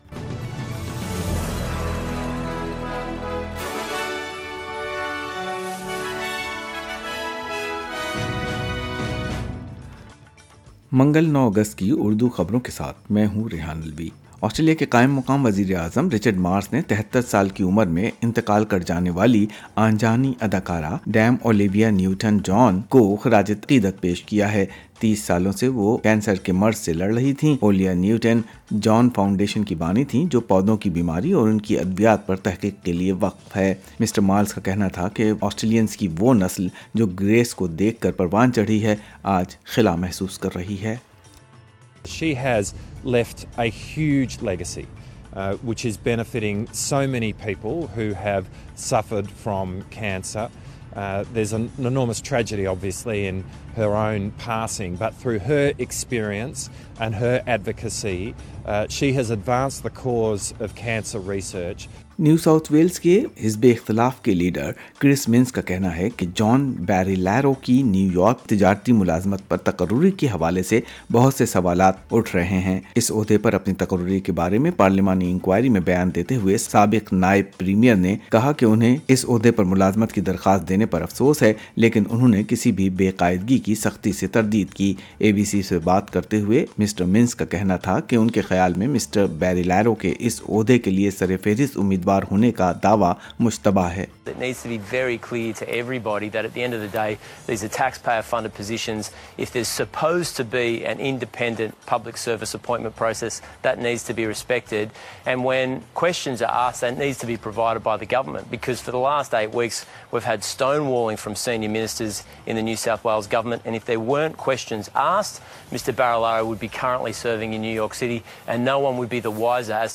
Urdu News Tue. 8 Aug. 2022